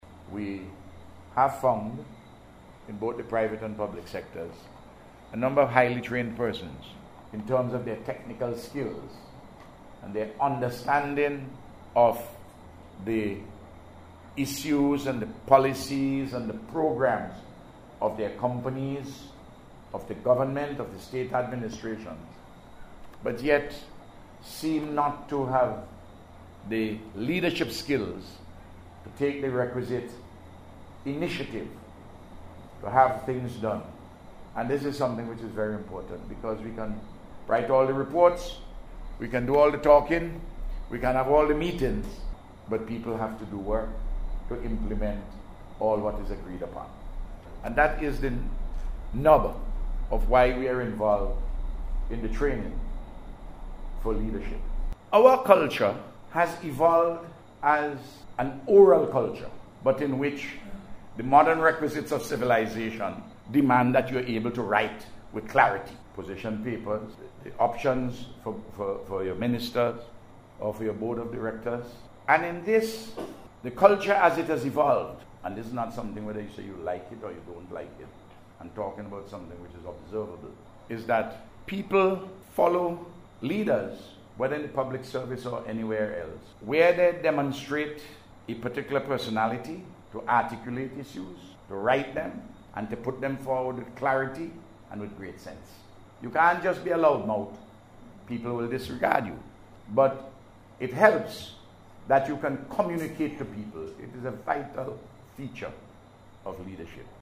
Delivering the feature address at the event, Prime Minister Dr. Ralph Gonsalves said the programme is an important part of the thrust to provide quality leadership to drive public and private sector development.